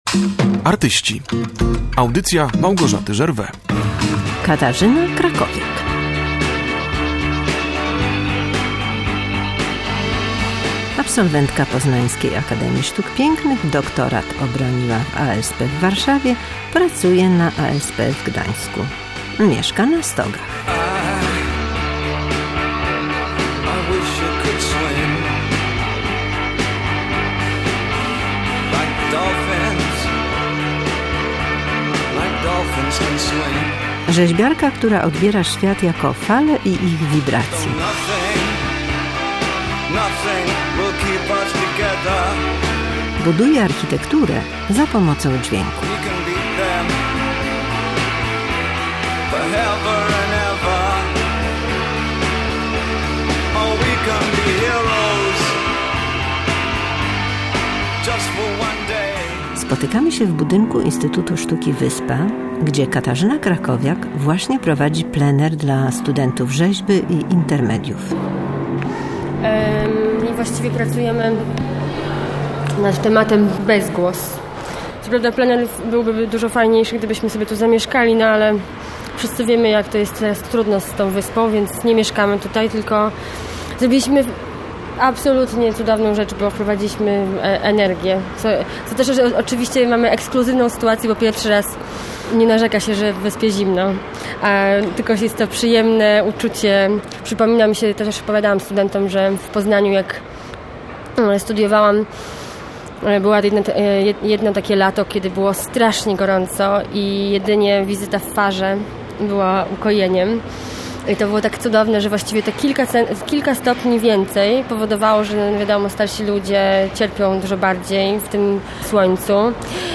W audycji usłyszymy kompozycję opartą o system ewakuacji budynku.